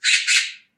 urraca1
magpie1　DL
magpie1.mp3